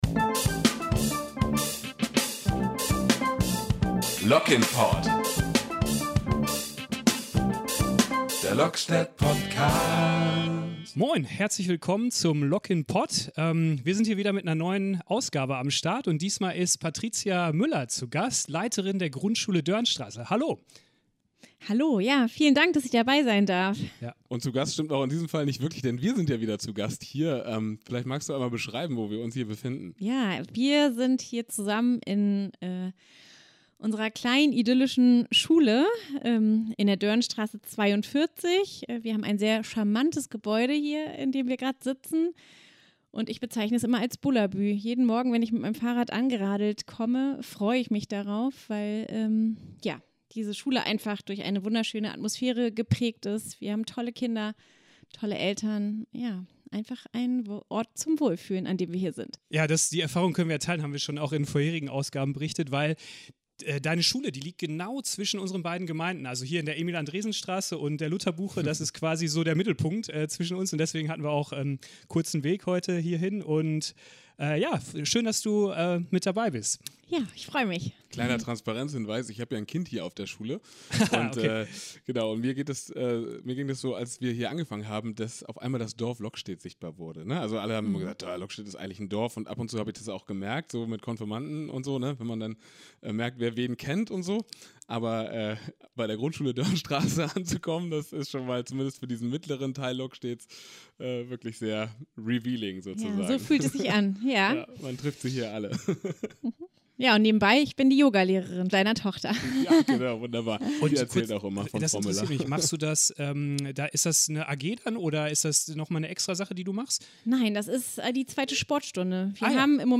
Ein inspirierendes Gespräch über Bildung, Gemeinschaft und Zukunft!